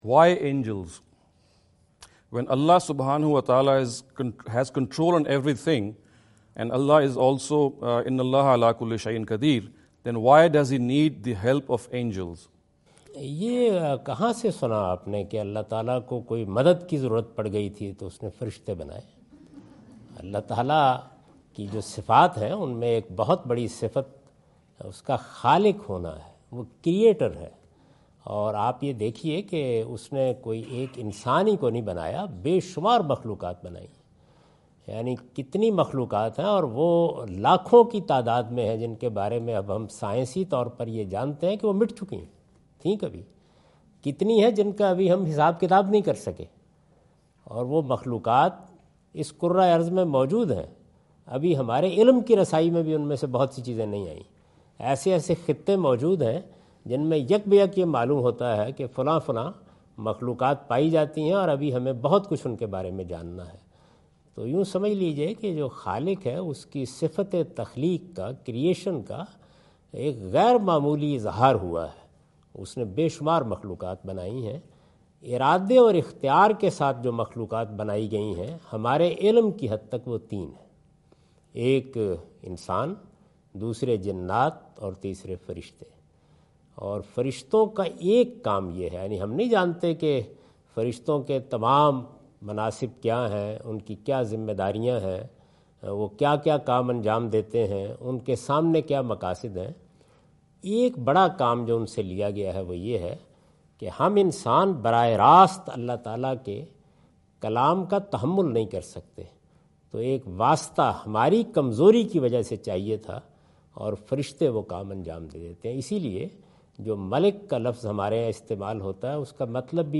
In this video Javed Ahmad Ghamidi answer the question about "why does Allah need angels?" asked at East-West University Chicago on September 24,2017.
جاوید احمد صاحب غامدی دورہ امریکہ2017 کے دوران ایسٹ ویسٹ یونیورسٹی شکاگو میں " اللہ کو فرشتوں کی ضرورت کیوں؟" سے متعلق ایک سوال کا جواب دے رہے ہیں۔